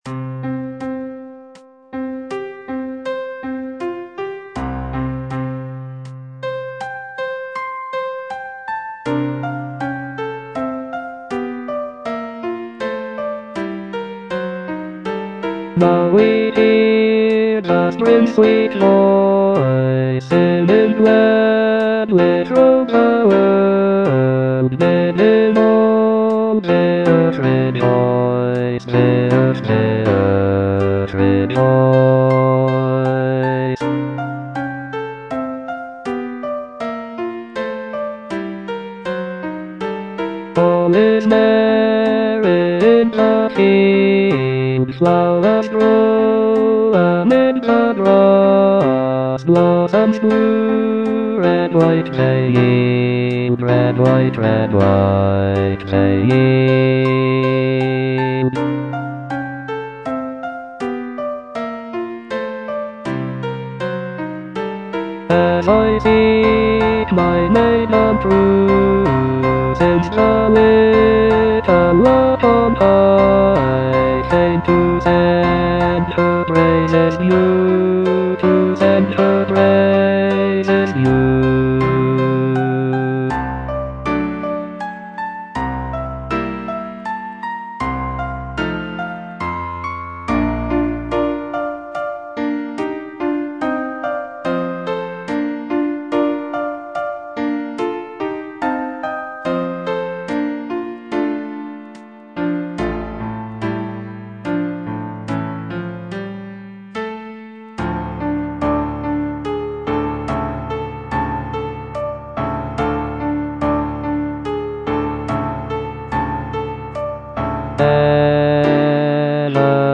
E. ELGAR - FROM THE BAVARIAN HIGHLANDS False love (bass II) (Voice with metronome) Ads stop: auto-stop